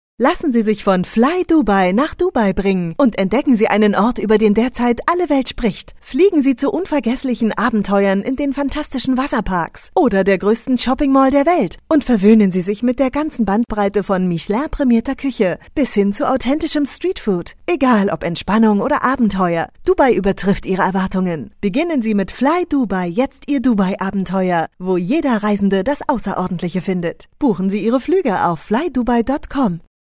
deutsche Sprecherin in Berlin und liebe es
klare und warme Stimme, Mezzosopran